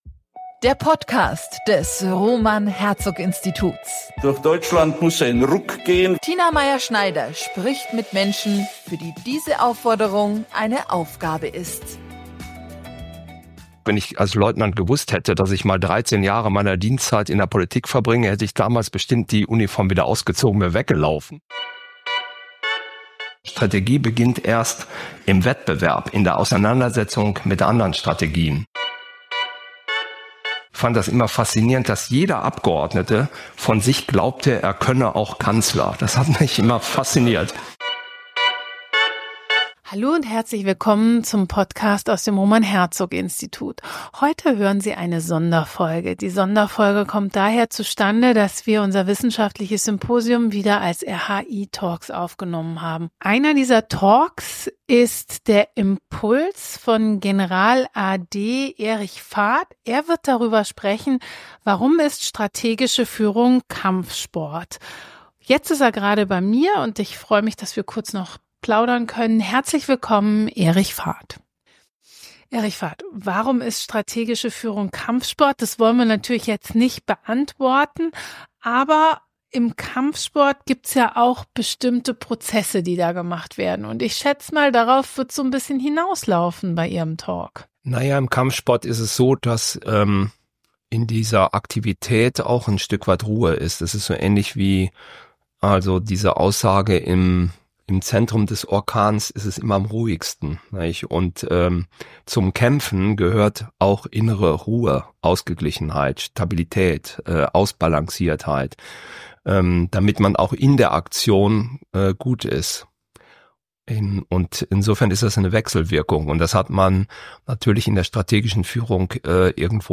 Was macht gute strategische Führung in Politik und Gesellschaft aus? Dieser Frage haben wir uns gemeinsam mit neun Expertinnen und Experten im Rahmen des RHI-Symposiums am 26.11.2024 gewidmet.